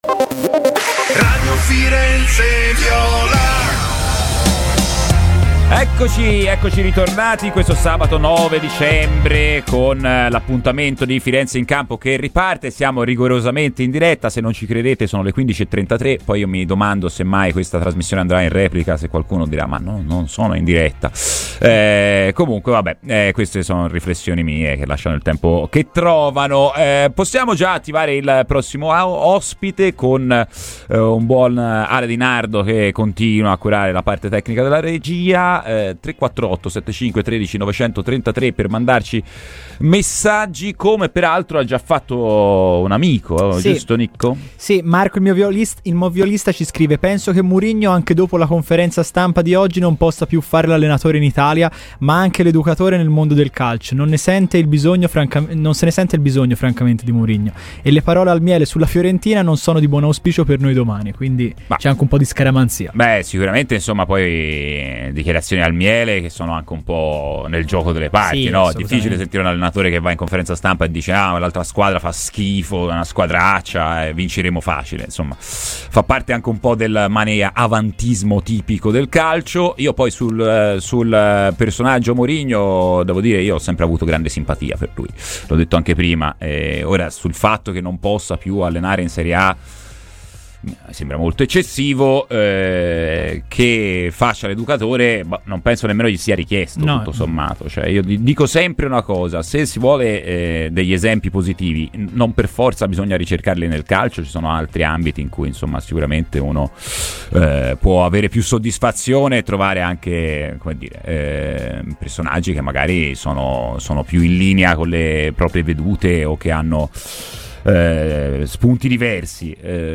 Radio Firenzeviola